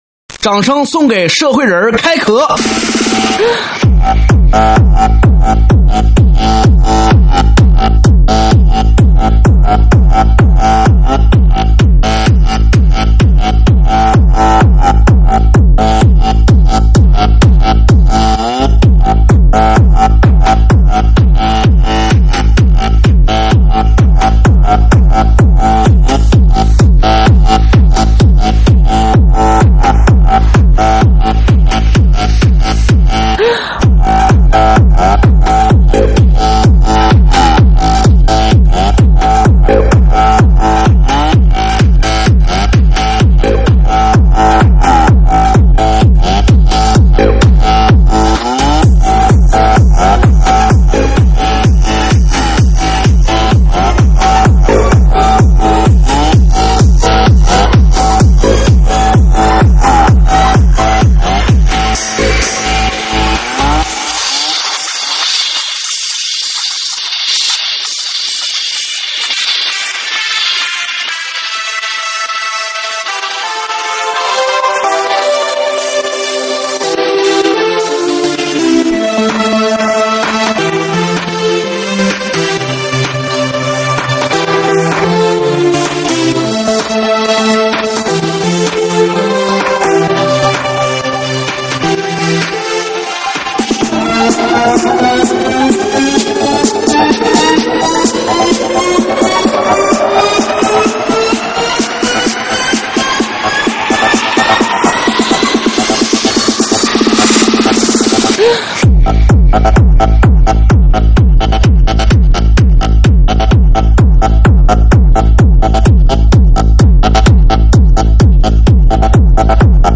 3D全景环绕